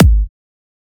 edm-kick-19.wav